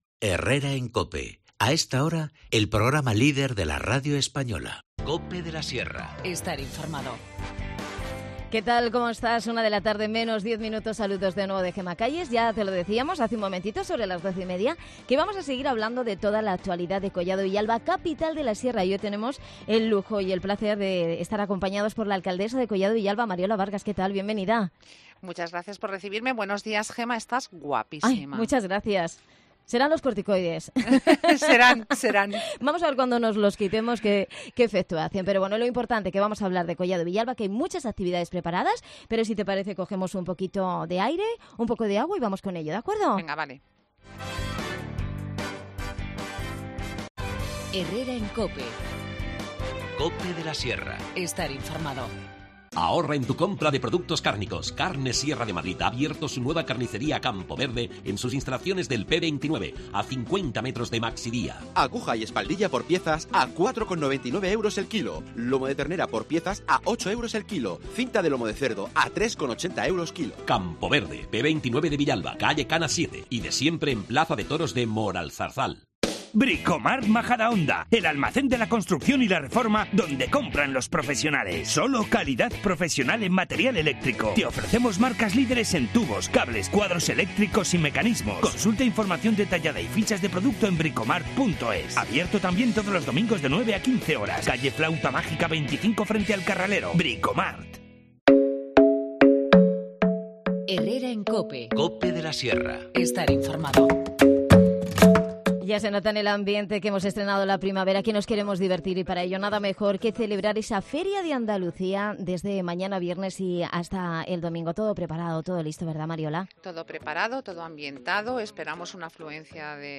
La alcaldesa de Collado Villalba, Mariola Vargas, nos visita para contarnos toda la actualidad del municipio. La Feria de Andalucía, las Jornadas de Cuidadores de Esclerosis Múltiple, la Feria Emprende y Emplea y el acto homenaje a los vecinos centenarios el viernes 10 de mayo.